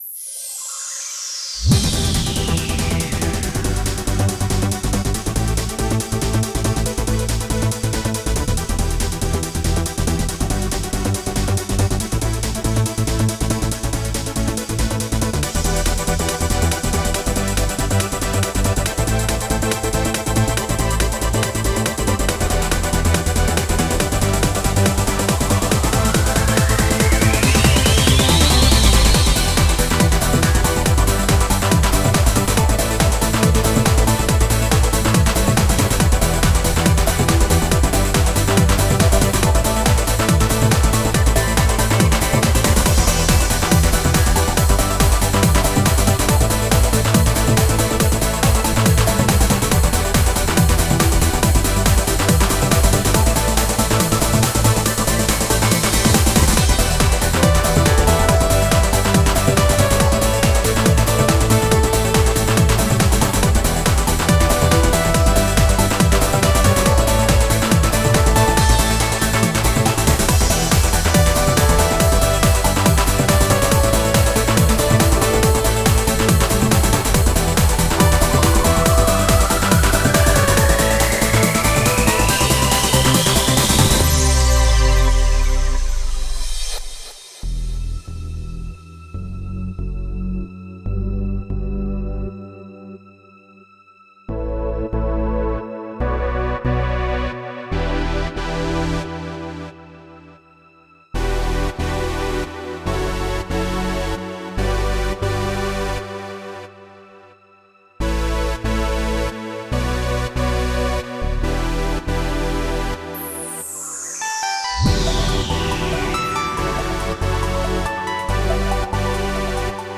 Style: Trance